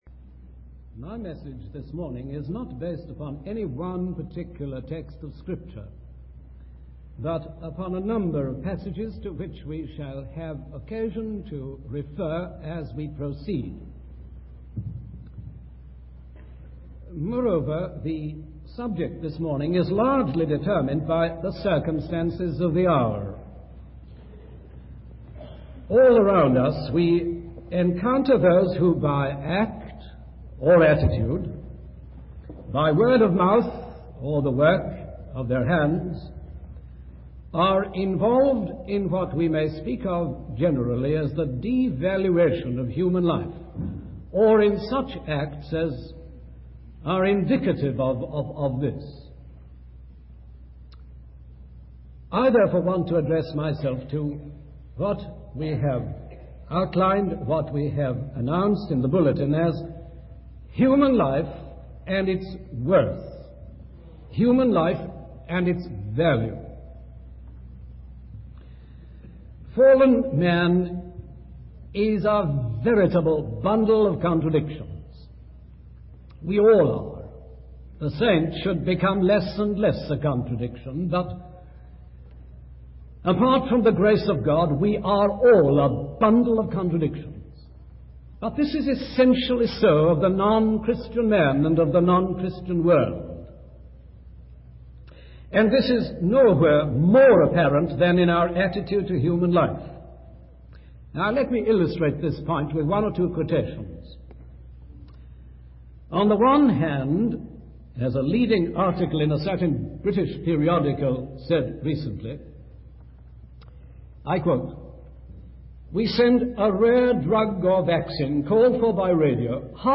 In this sermon, the speaker emphasizes the unique value and dignity of human life.